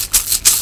Closed Hats
07_Hats_19_SP.wav